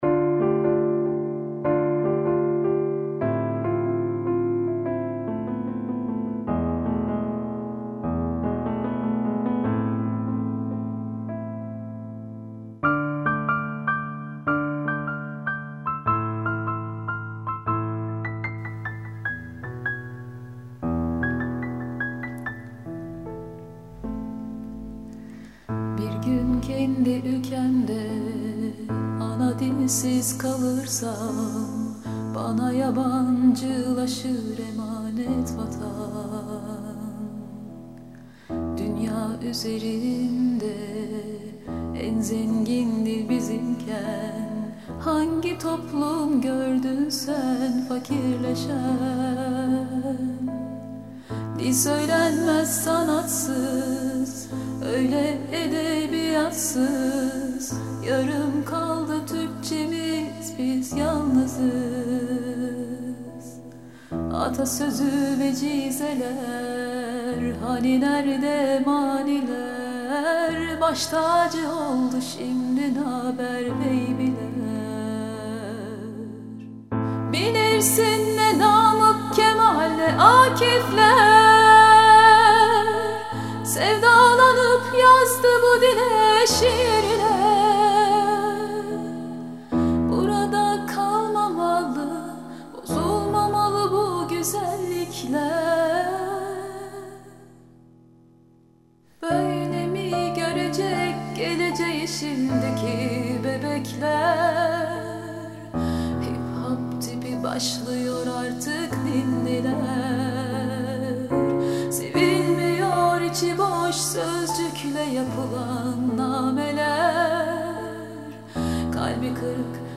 Ana_Dilsiz_kalirsam_ROCK.mp3